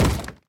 Minecraft / mob / zombie / wood2.ogg